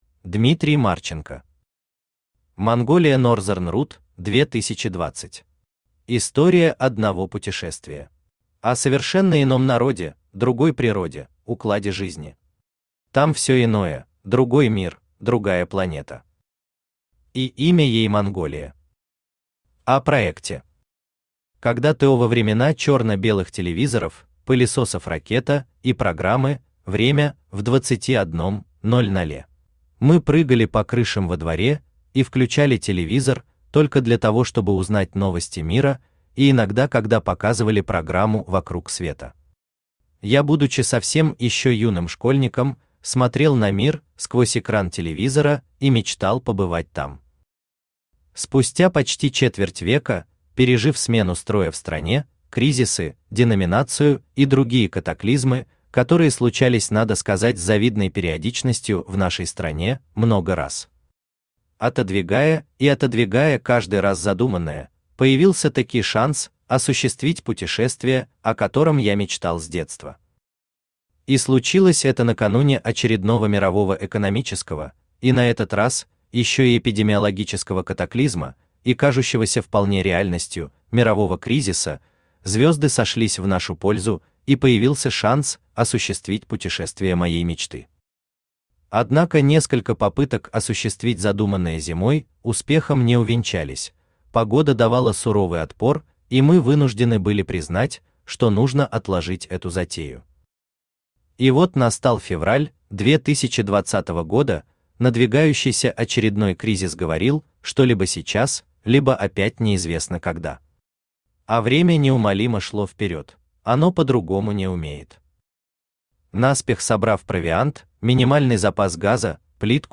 Аудиокнига Монголия Northern route – 2020. История одного путешествия | Библиотека аудиокниг
История одного путешествия Автор Дмитрий Валерьевич Марченко Читает аудиокнигу Авточтец ЛитРес.